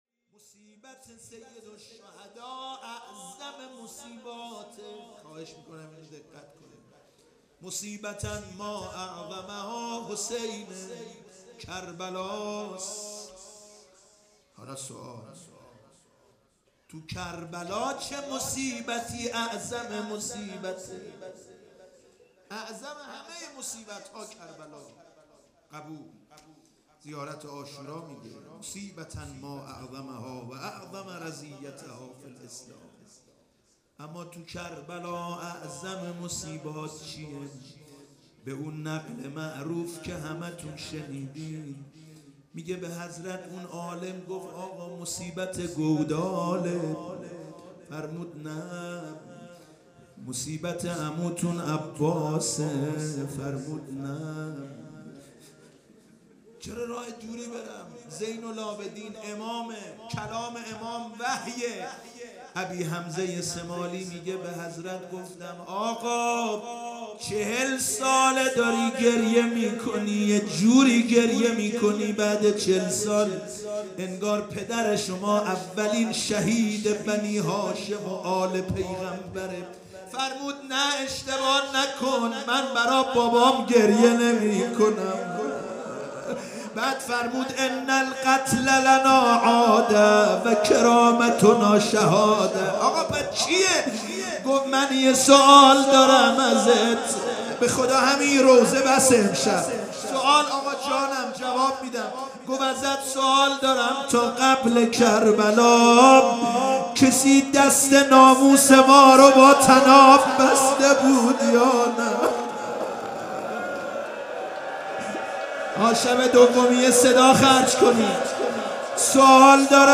2  روضه ی اعظم مصیبات در کربلا (روضه)